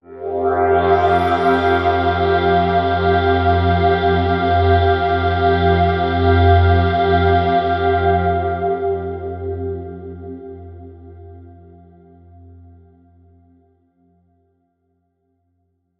Here’s a test with the same 3 sounds on both hardware and software, all recorded into Digitakt II.
Its still got the same overall character as the hardware, but there’s clearly some differences in the software version, probably partly due to the updated effects (the software reverb actually doesnt glitch like the hardware does).